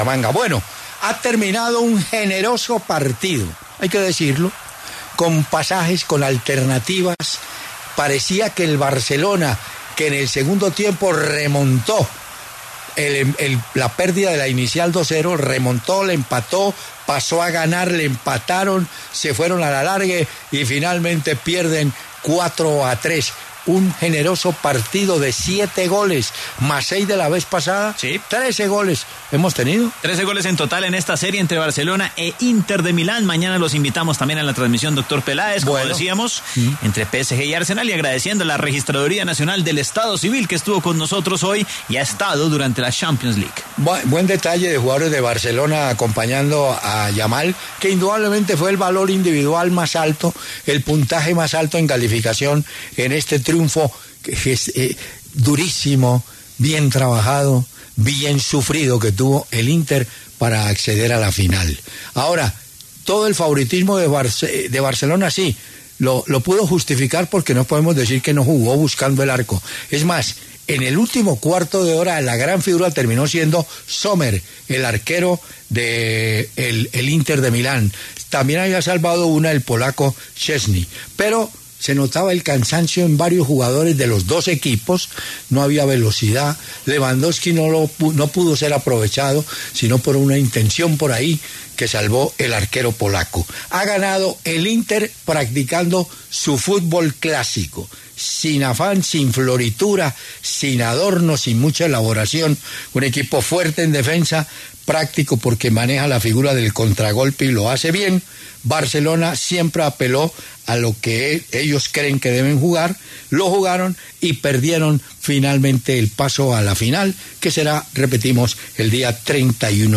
Apropósito, Hernán Peláez analizó el partido que se tuvo que ir al tiempo extra tras unos 90 minutos agónicos en los que parecía que el finalista iba a ser el Barcelona, pero un gol de Acerbi en el tiempo adicional obligo a que se jugara suplementario.